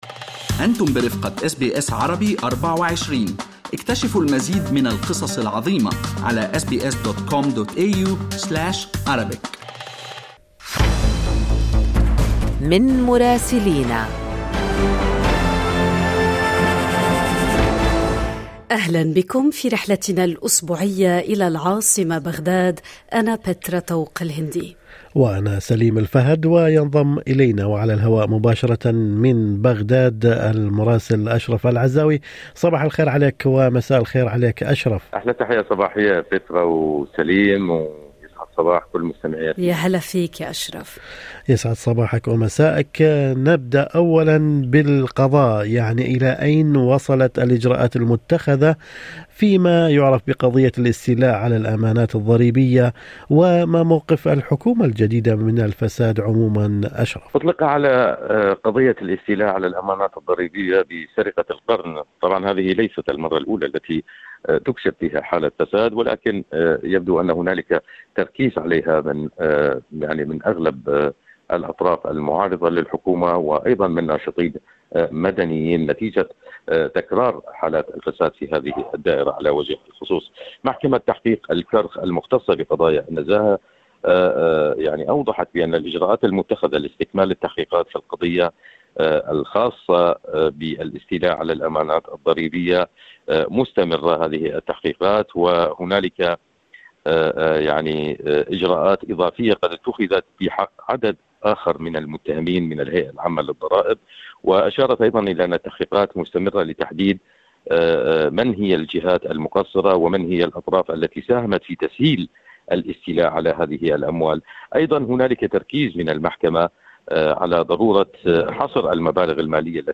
يمكنكم الاستماع إلى تقرير مراسلنا في العاصمة العراقية بغداد بالضغط على التسجيل الصوتي أعلاه.